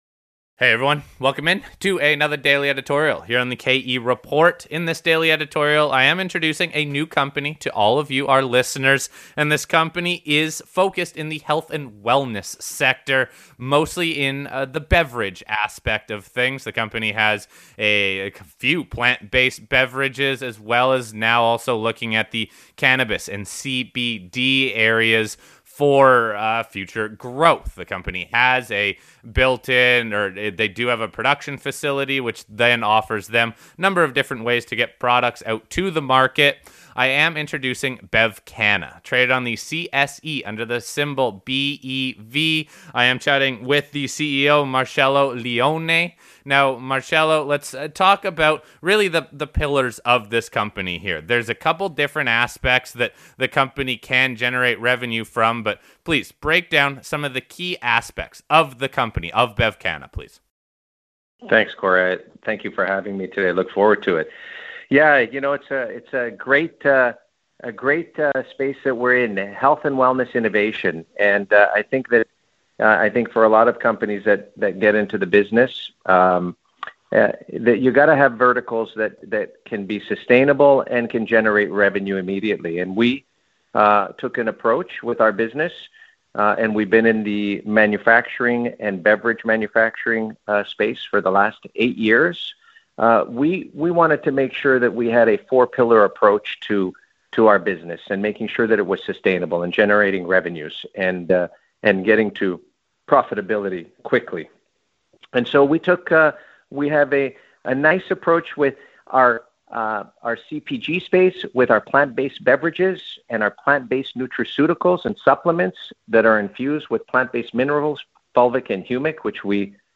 As I continue to highlight companies in a wide range of sectors please let me know what you think of the Company and if you have any questions following up on the interview.